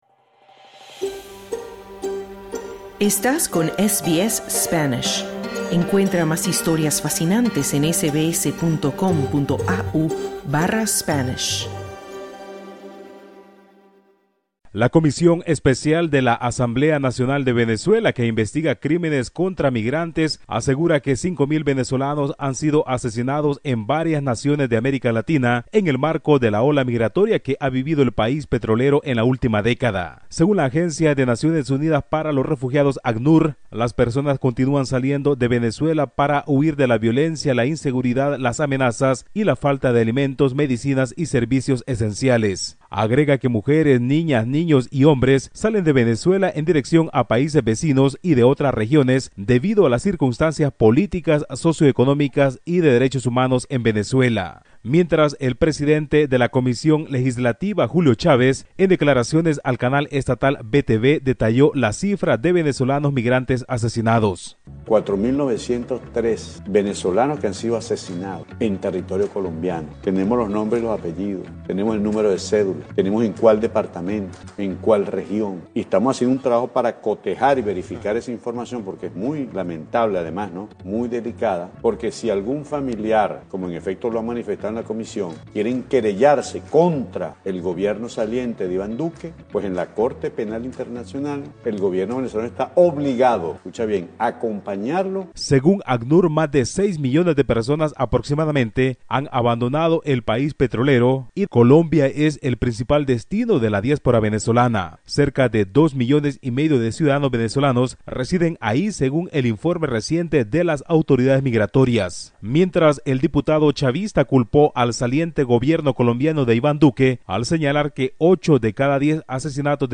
Escucha el informe del corresponsal de SBS Spanish en Latinoamérica